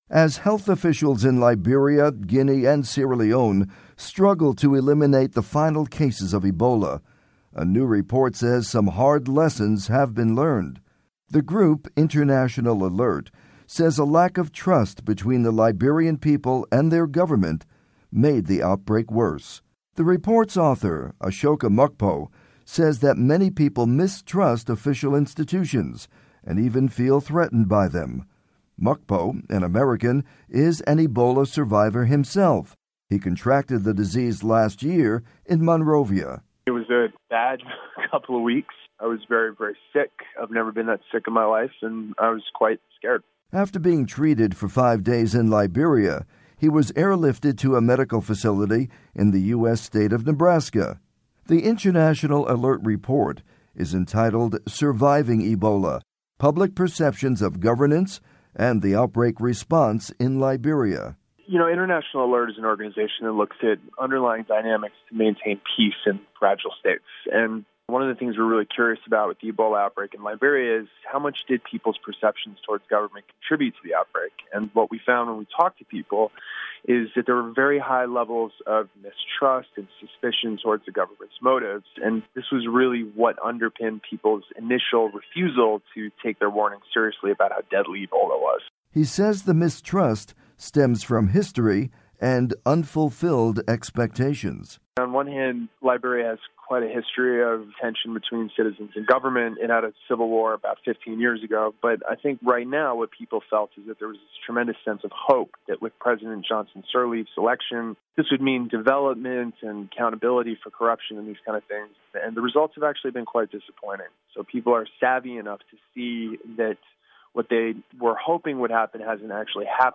Report: Ebola Fueled by Distrust